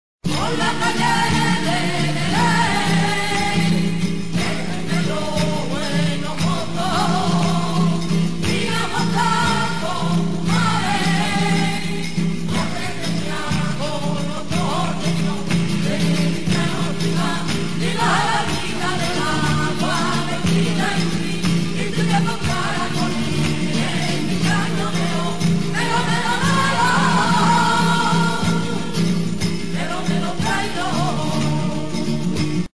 Fragmento de 'El melo, melo', cantado por Gitanos de Jerez